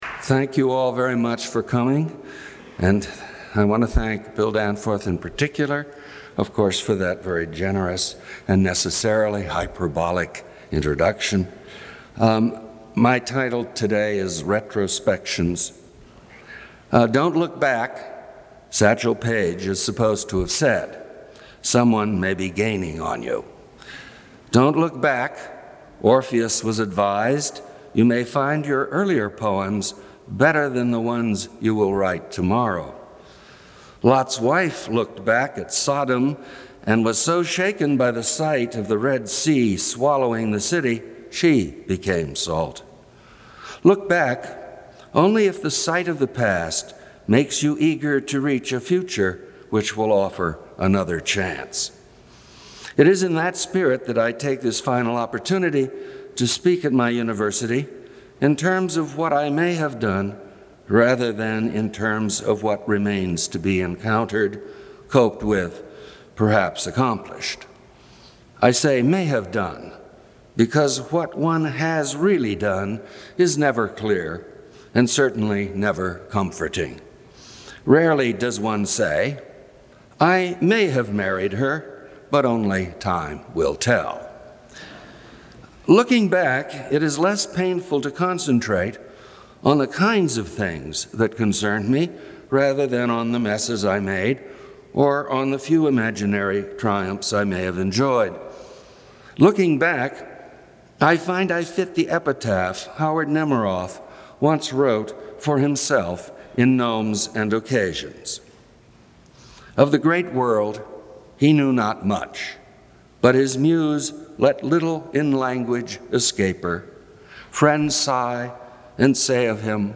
Reading and Commentary: "Retrospections"